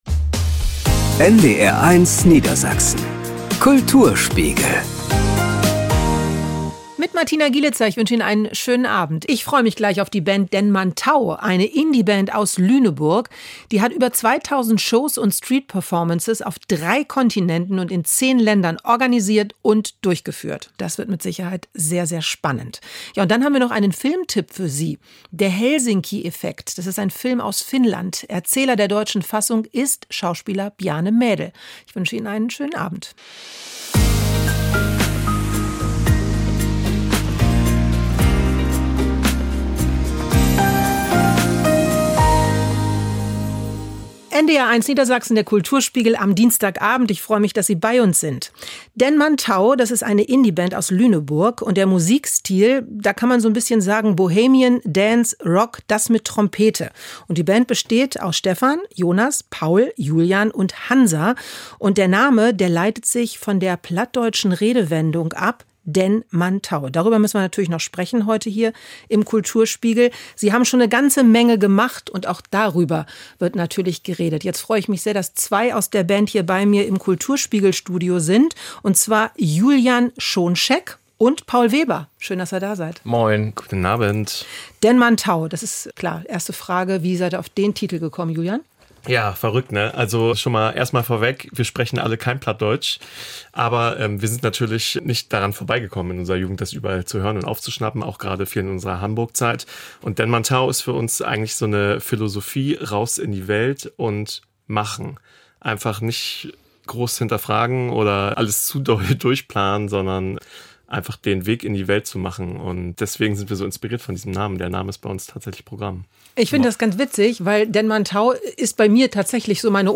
DenManTau im Interview ~ NDR 1 Niedersachsen - Kulturspiegel Podcast